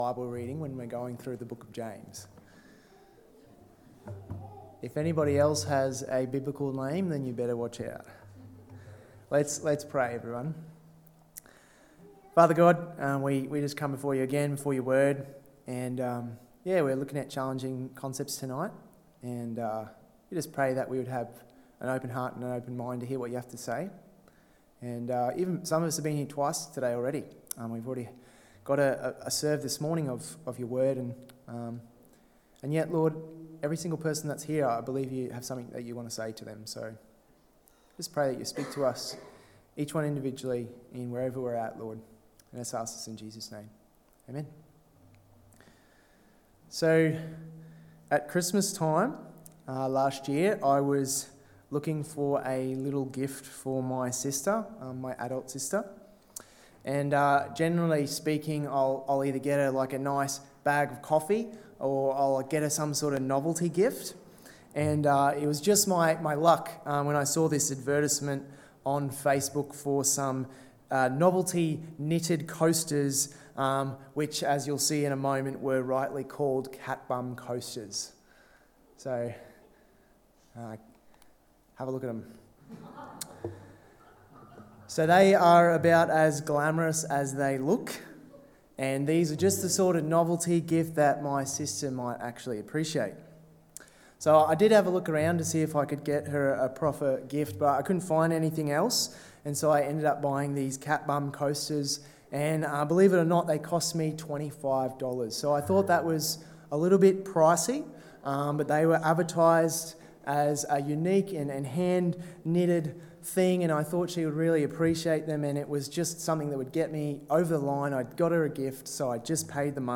Wealth and Priorities (James 1:9-11, 4:13-5:6 Sermon) 30/04/23 Evening